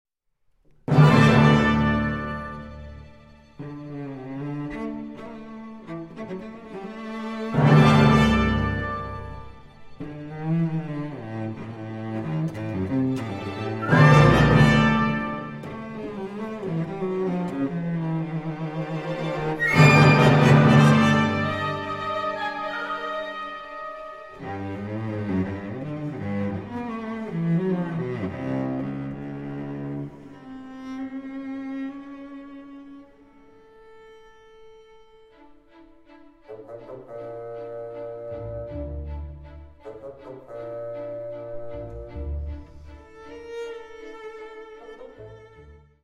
cello
Recorded in the Presence of the Composer